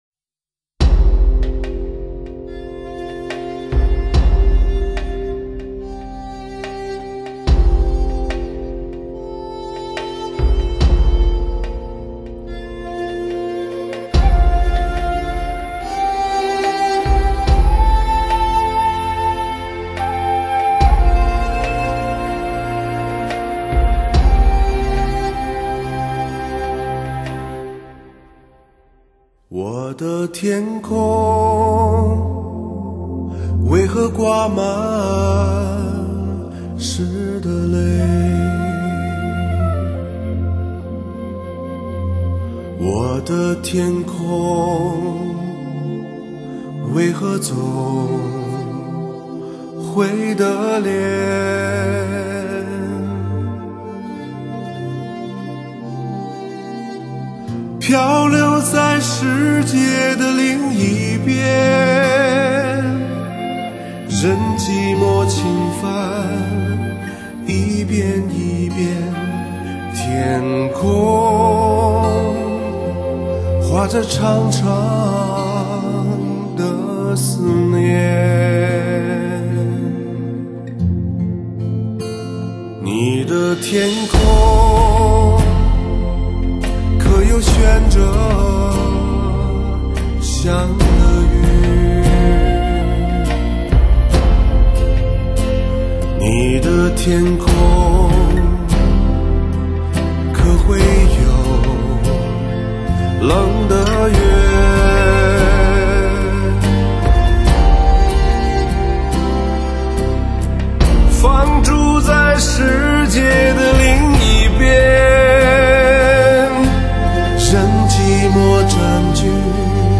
母带由日本JVC MASTERING CENTER将母带重新处理  效果更佳更超越
自然流畅的温暖男人声线，深邃寂寥听的人心莫名感动